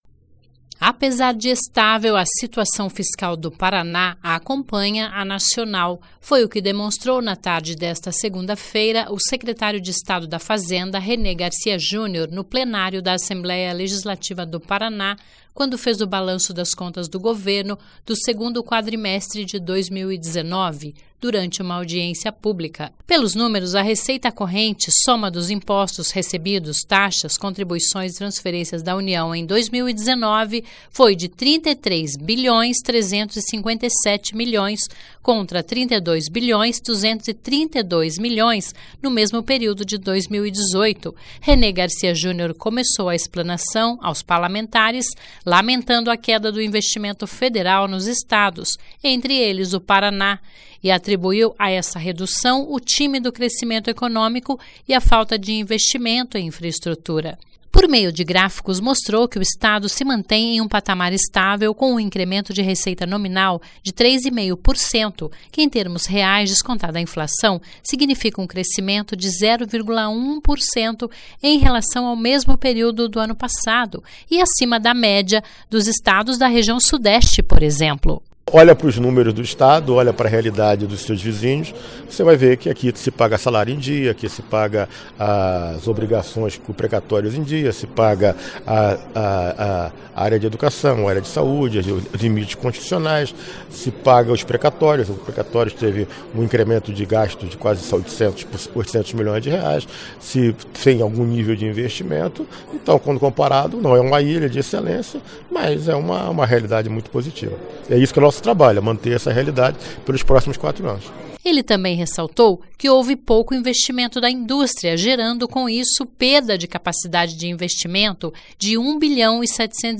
Apesar de estável, a situação fiscal do Paraná acompanha a nacional. Foi o que demonstrou, na tarde desta segunda-feira (30), o secretário de Estado da Fazenda, Renê Garcia Junior, no plenário da Assembleia Legislativa do Paraná (Alep), quando fez o balanço das contas do Governo do segundo quadrimestre de 2019, durante uma audiência pública.
O presidente da Assembleia Legislativa do Paraná, deputado Ademar Traiano (PSDB) comentou os números apresentados pelo secretário da Fazenda, fazendo uma análise positiva.